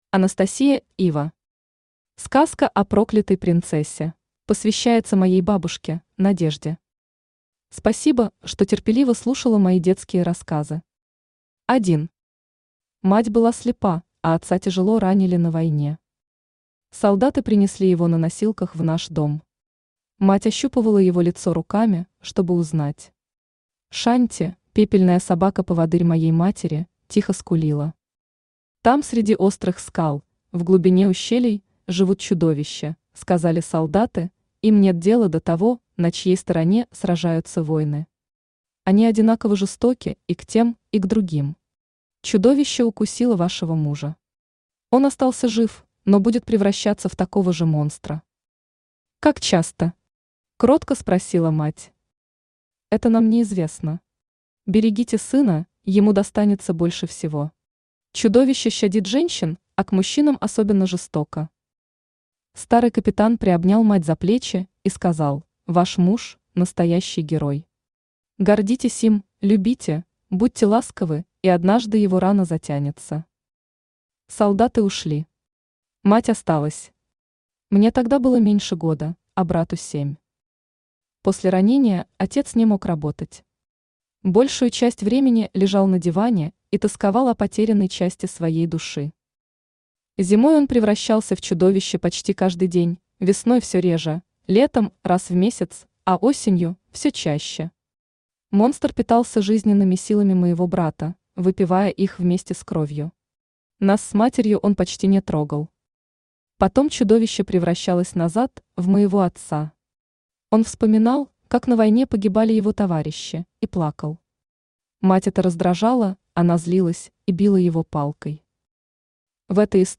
Читает: Авточтец ЛитРес
Аудиокнига «Сказка о проклятой принцессе».